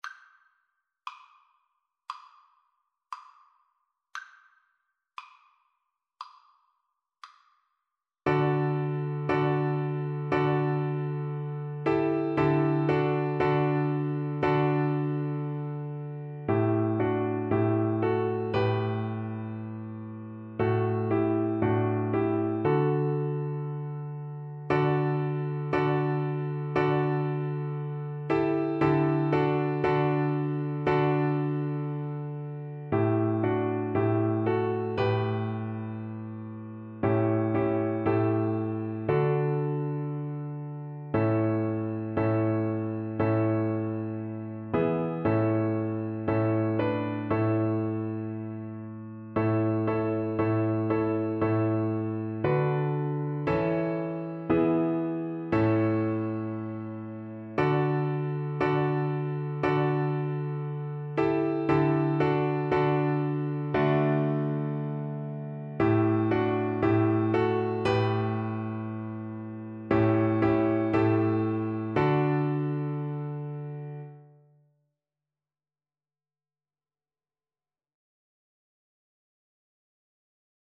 4/4 (View more 4/4 Music)
Andante maestoso =c.80